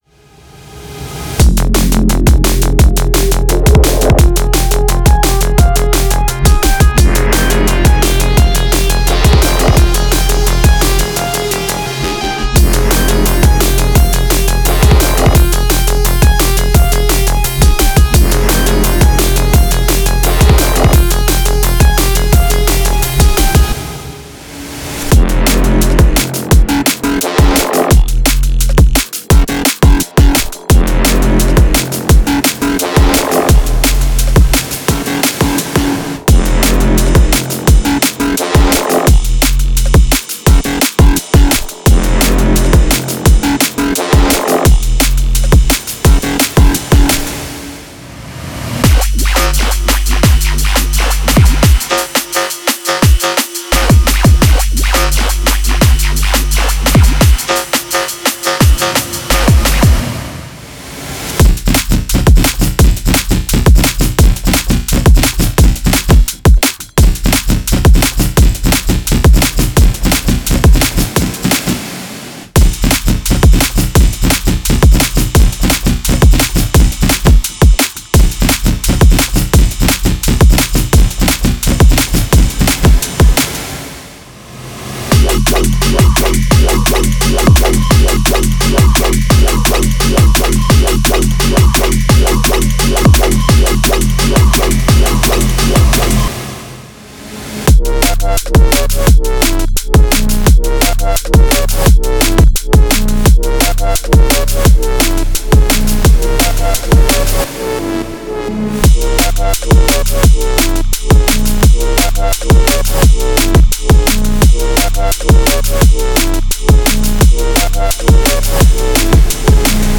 Breaks Drum & Bass